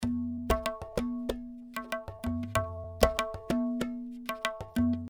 Tabla loops 95 bpm
This is an Indian tabla drum loops (scale A), playing a variety of styles.
Played by a professional tabla player.
The tabla was recorded using one of the best microphone on the market, The AKG C-12 VR microphone. The loops are mono with no EQ, EFFECT or DYNAMICS, but exported stereo for easy Drop and play .